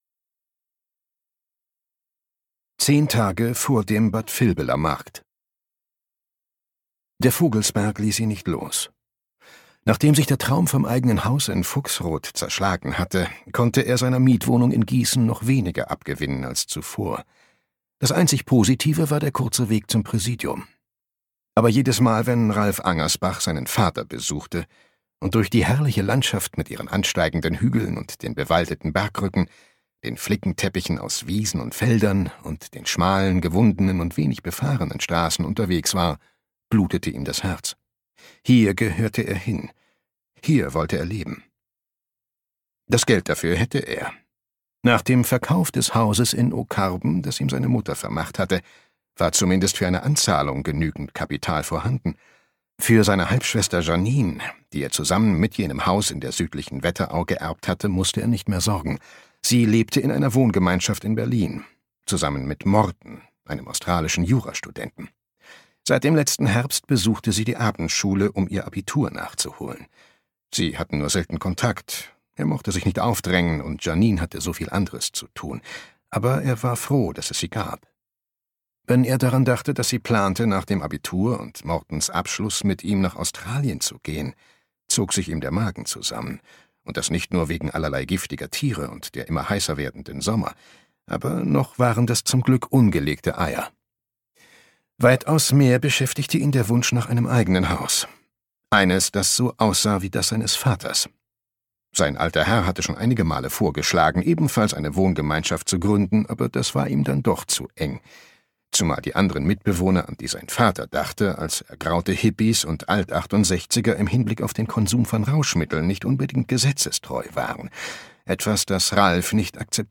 Kriminalroman
Gekürzt Autorisierte, d.h. von Autor:innen und / oder Verlagen freigegebene, bearbeitete Fassung.